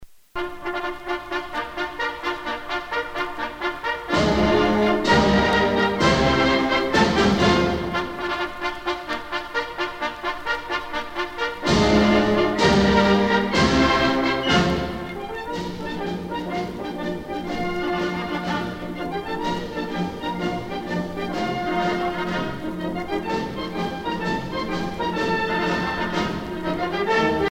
à marcher
Pièce musicale éditée